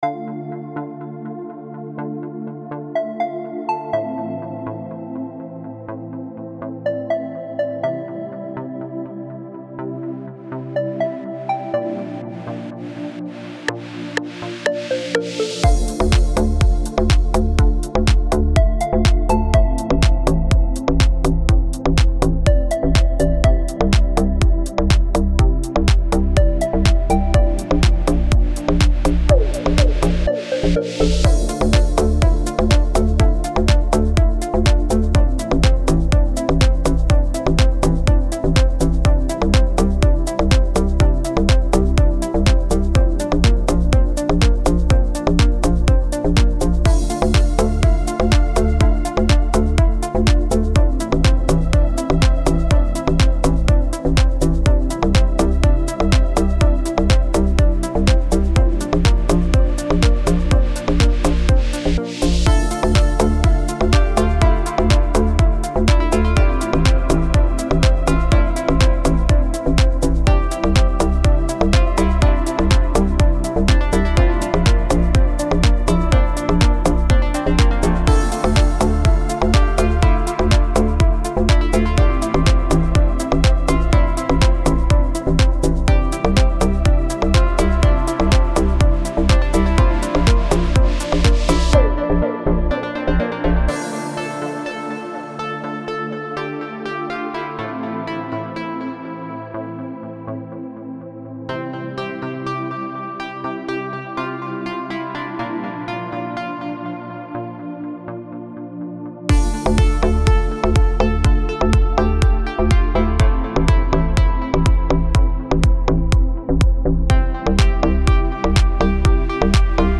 دیپ هاوس
ریتمیک آرام